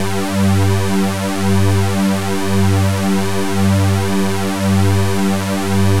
G2_jx_phat_lead_1.wav